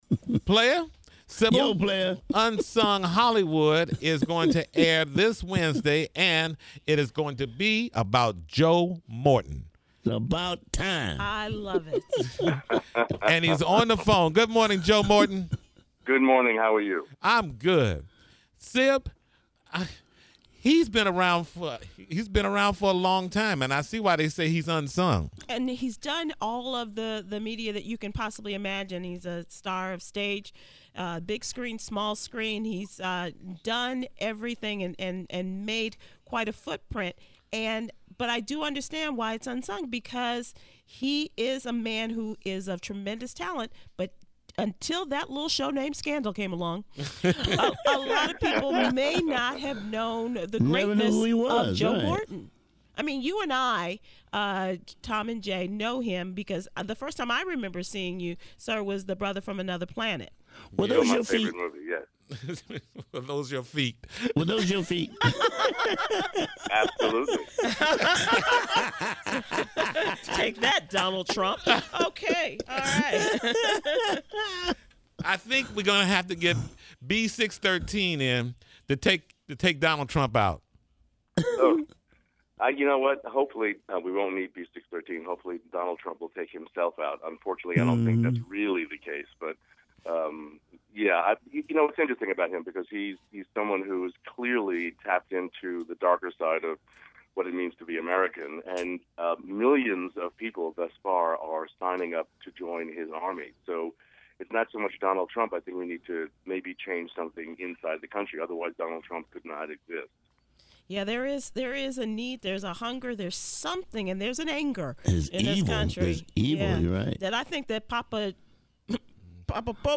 Joe Morton is the next star to be featured on TV One’s Unsung Hollywood. Listen to the interview above to hear more about the episode and Morton’s off-camera relationship with Kerry Washington.